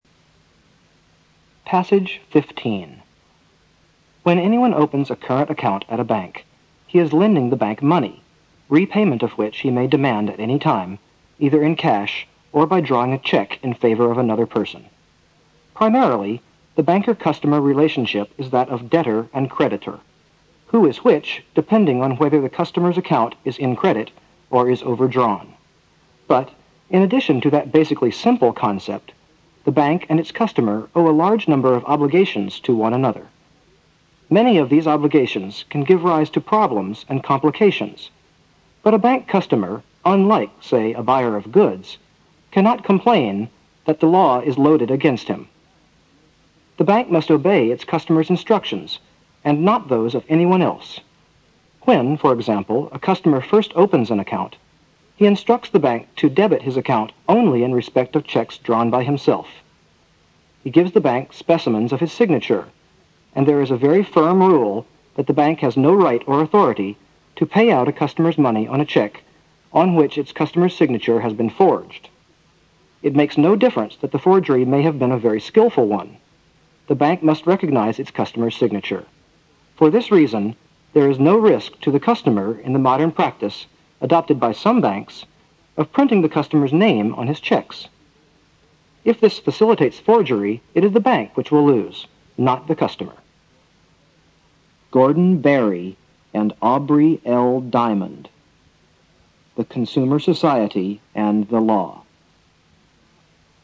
新概念英语85年上外美音版第四册 第15课 听力文件下载—在线英语听力室